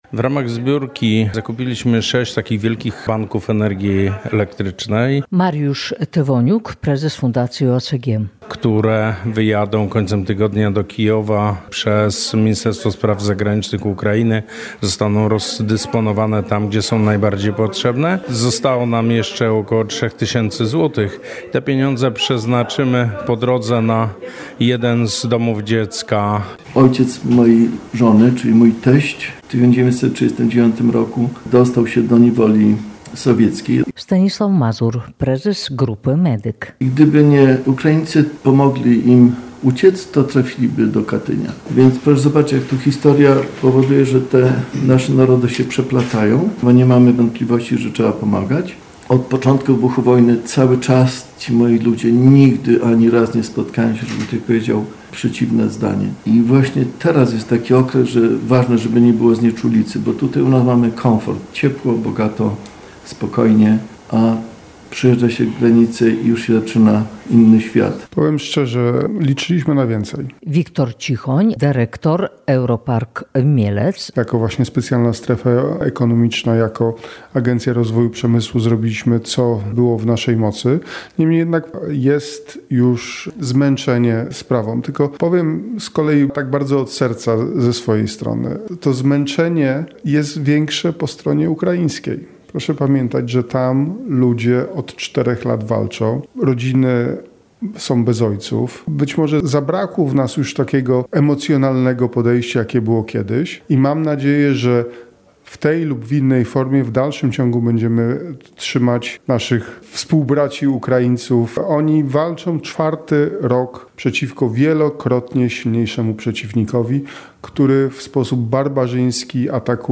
Relacje reporterskie • Zakończyła się akcja humanitarna „Solidarni z Kijowem”, podczas której zebrano ponad 36 tysięcy złotych.